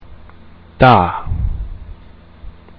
(Pronunciation)